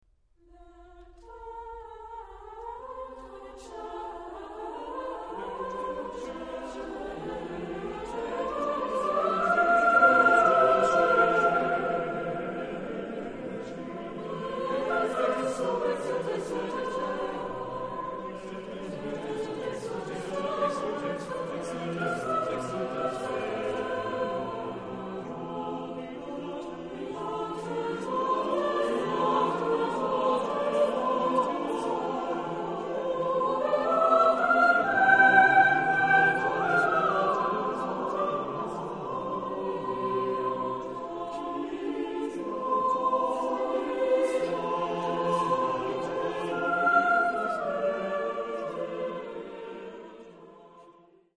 Genre-Style-Forme : Motet ; Sacré
Type de choeur : SATTB  (5 voix mixtes )
Tonalité : fa majeur